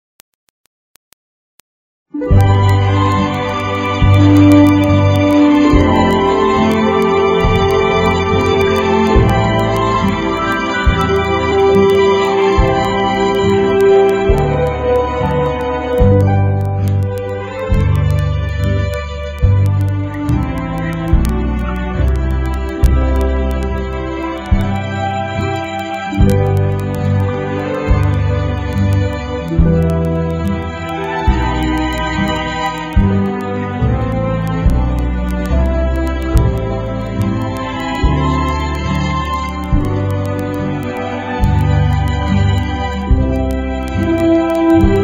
NOTE: Background Tracks 11 Thru 22